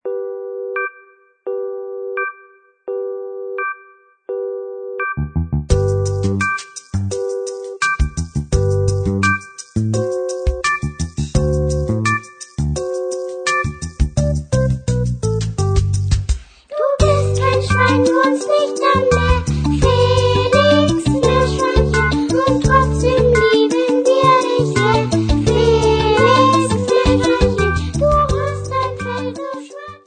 für eine oder zwei Sopranblockflöten
Besetzung: 1-2 Sopranblockflöten mit CD